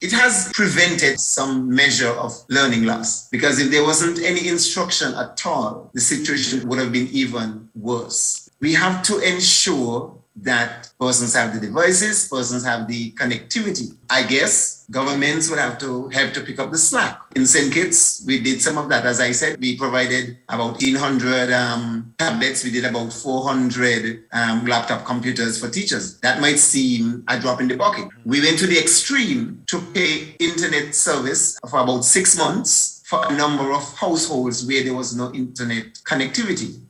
During a recent discussion on Education and the Pandemic, Permanent Secretary in the Ministry of Education (St. Kitts), Mr. Vincent Hodge, addressed the “Learning Loss” of students and elaborated on how online learning during school closures has alleviated severe “Learning Loss”: